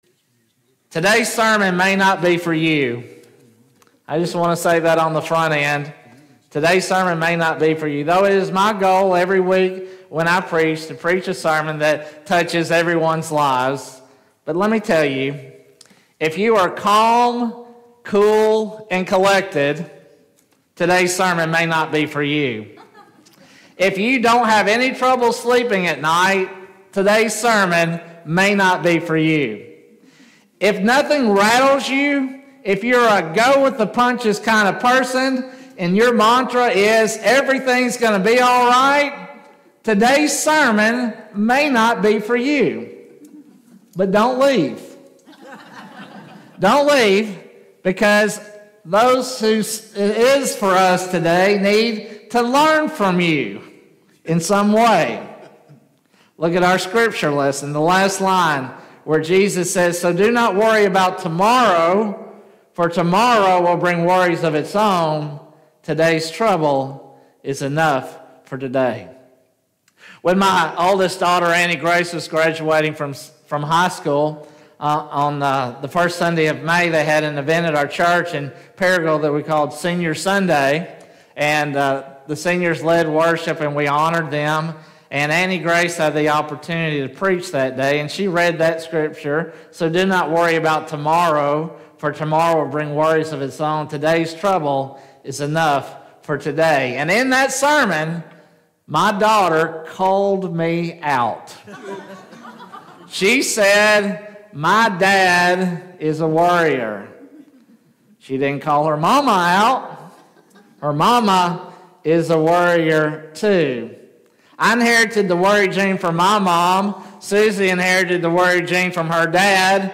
2024 Current Sermon Why Are You Anxious?